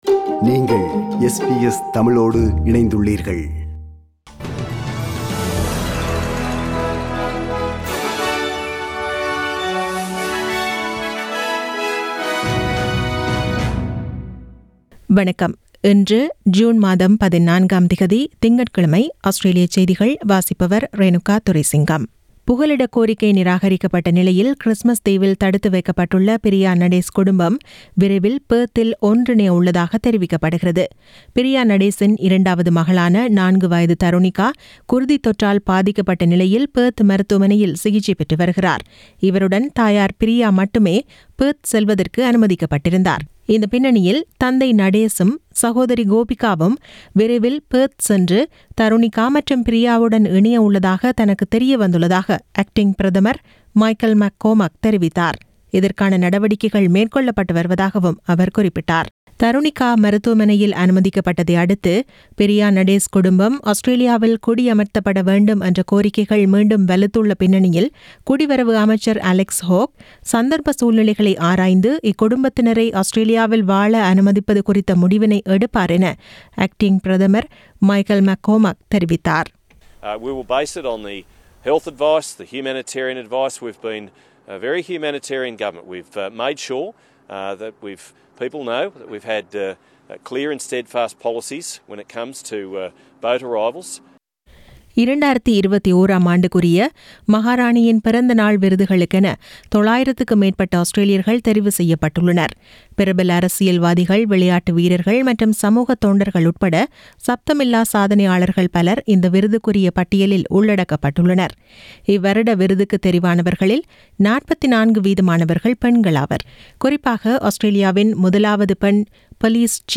Australian news bulletin for Monday 14 June 2021.